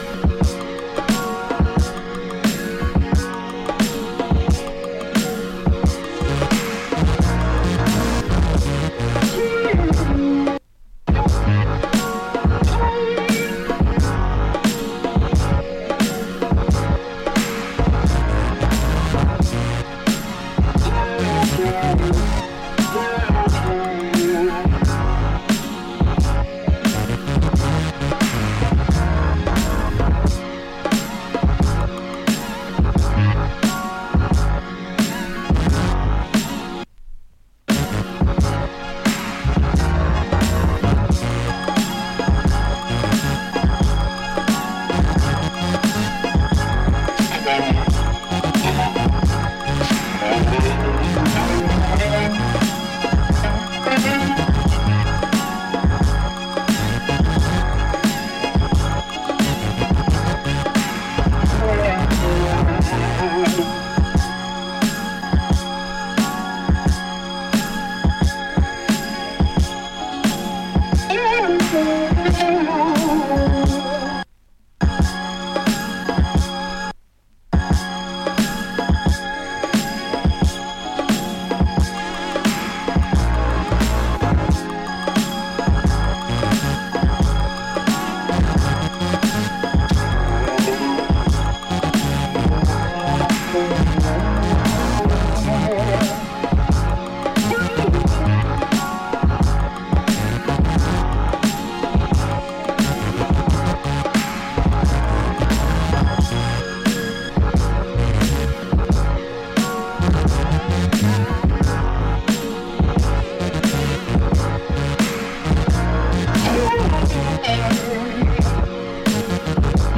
A mix of funky r&b, rap and electro.